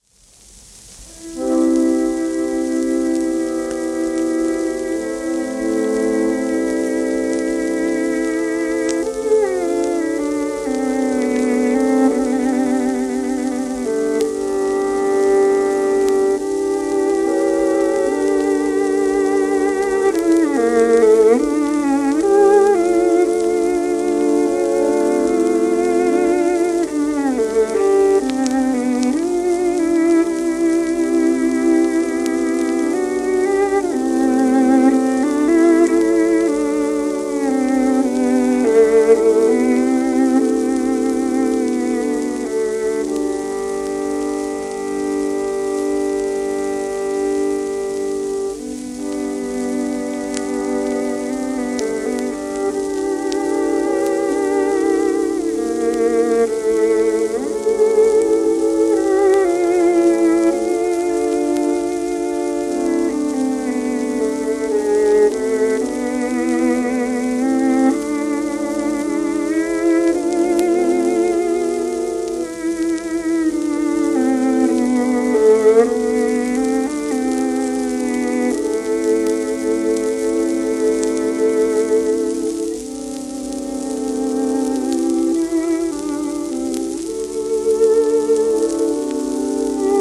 ヴァーサ・プシホダ(Vn:1900-60)
w/オーケストラ
1925年頃録音